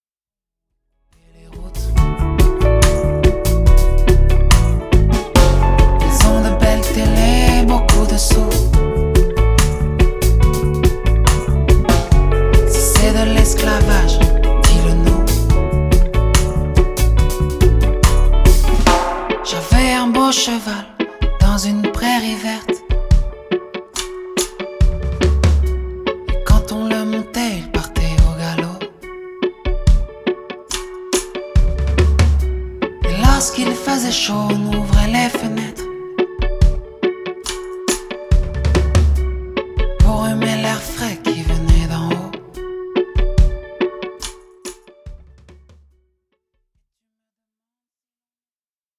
reggae
Enregistré dans un grand studio de Bruxelles